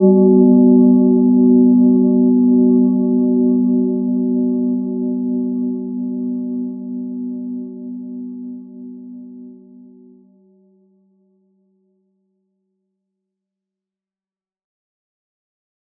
Gentle-Metallic-2-B3-p.wav